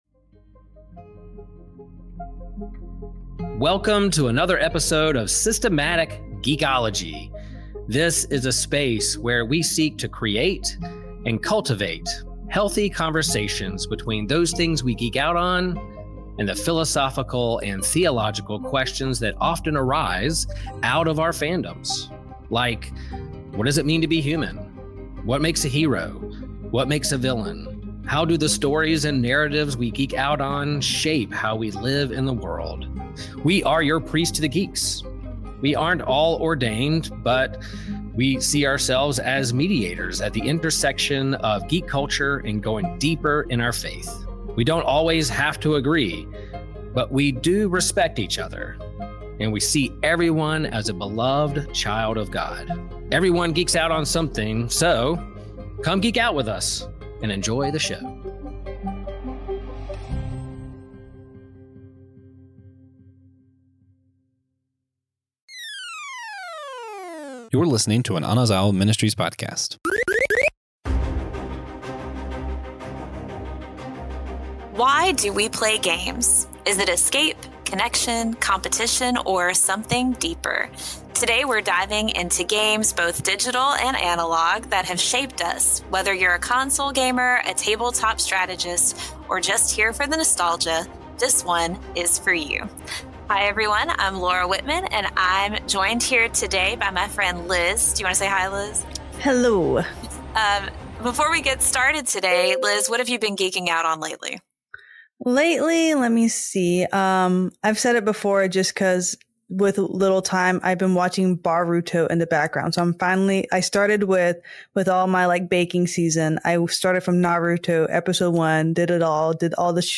With a blend of heartfelt reflections and witty banter, they discuss the nuances of character development and the importance of compassionate mentorship. Tune in for a relaxed yet insightful conversation that’s bound to resonate with fans and newcomers alike as they unpack the dynamics of good versus evil through the lens of these iconic characters. The inaugural episode of 'The Faces Behind Us' dives deep into the intricate dynamics between Zuko, Fire Lord Ozai, and Uncle Iroh from Avatar: The Last Airbender.